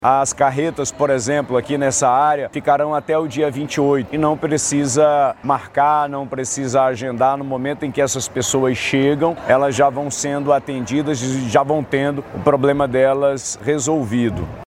SONORA-2-WILSON-LIMA.mp3